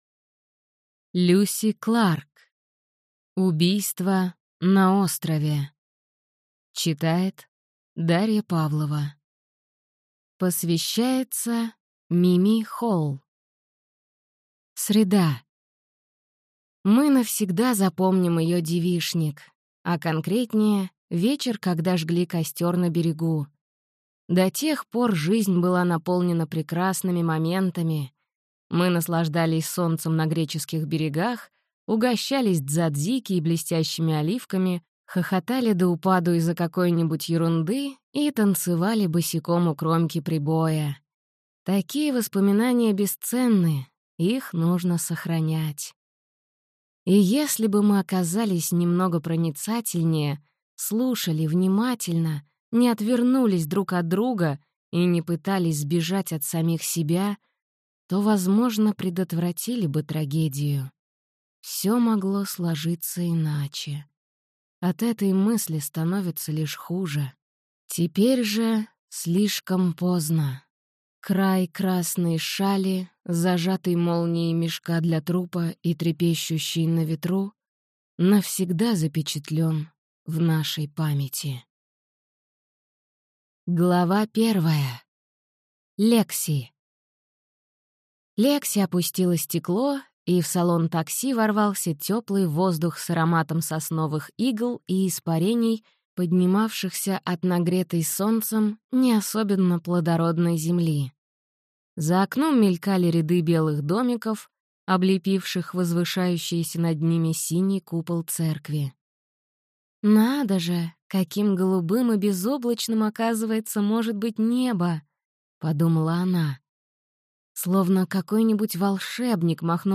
Аудиокнига Убийство на острове | Библиотека аудиокниг